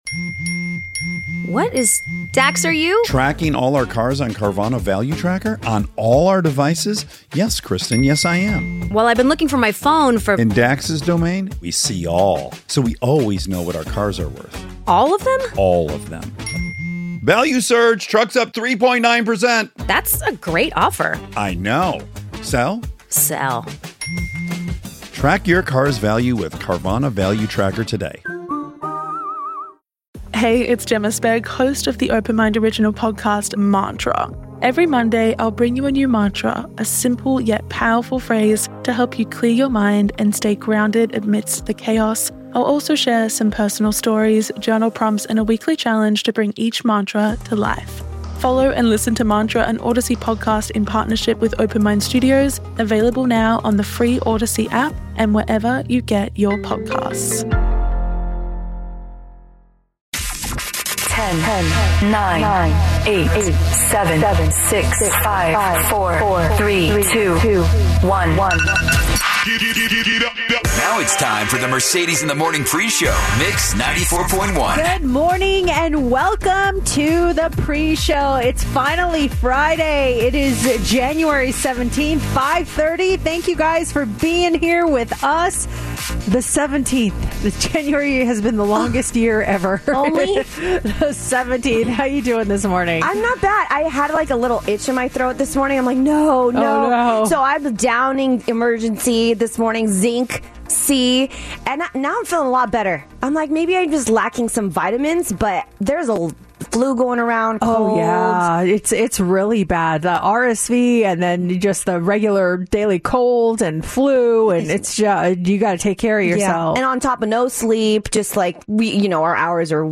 Three local, Las Vegas friends discuss life, current events, and everything else that pops into their heads.